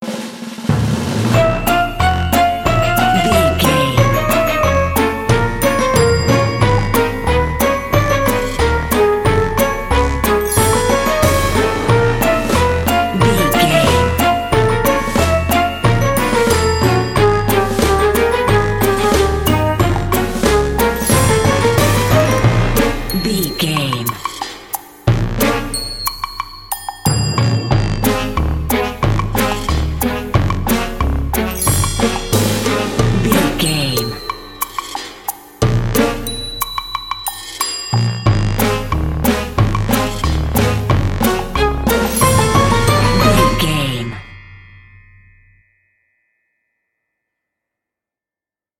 Aeolian/Minor
B♭
percussion
synthesiser
horns
strings
circus
goofy
comical
cheerful
perky
Light hearted
quirky